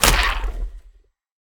creaking_heart_break.ogg